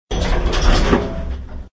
SElevatorDoorClose.ogg